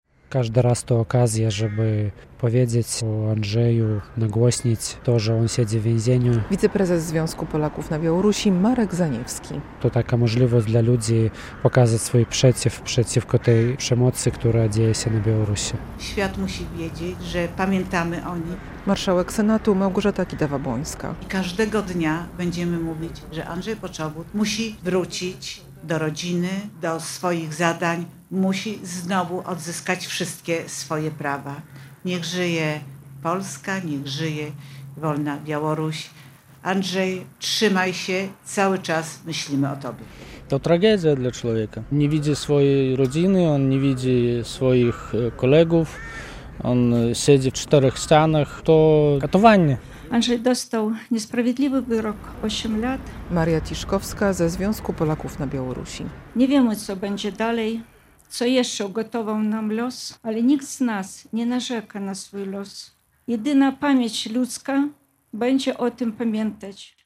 Wzięła ona udział w comiesięcznej akcji protestacyjnej w centrum Białegostoku.
Głos zabrali także przyjaciele Andrzeja Poczobuta.